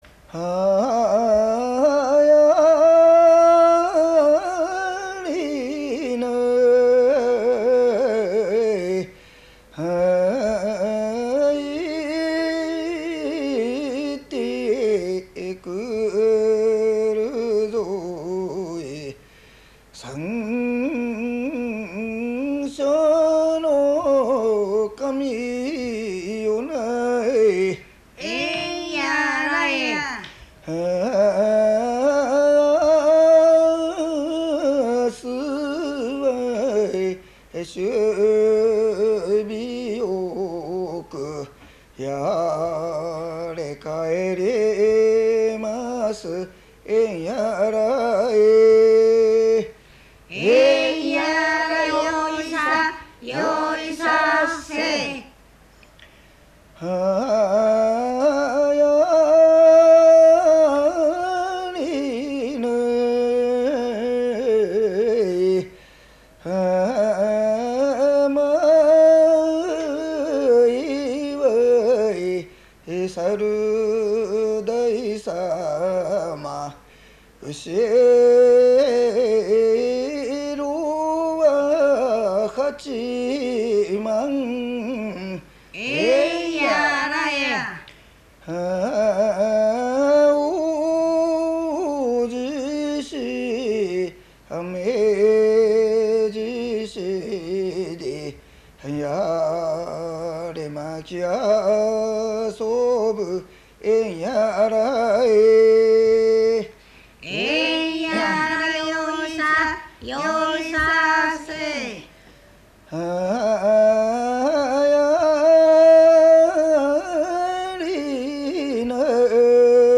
105 76 5 鴨川市 天津小湊町 萩原
獅子舞の歌（かつぎ出しの歌） 舞歌